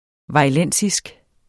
Udtale [ vɑjˈlεnˀsisg ]